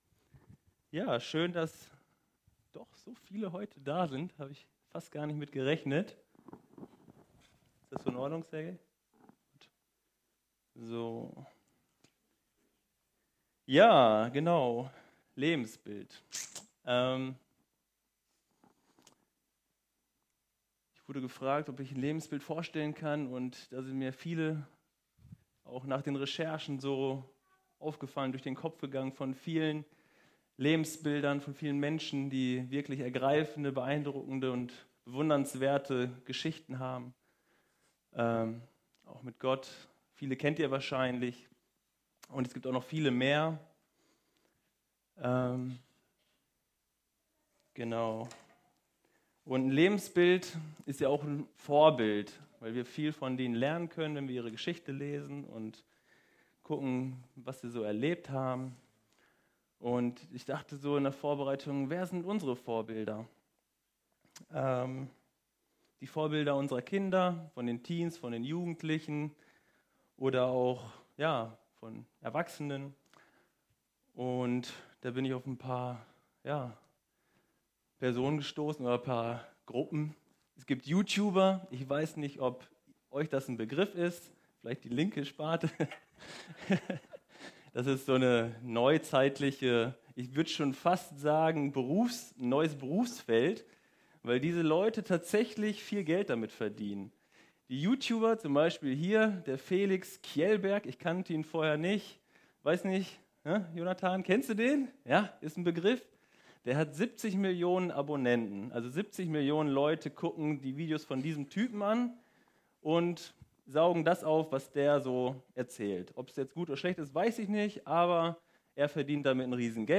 Predigt vom 11. August 2019 – efg Lage
Predigt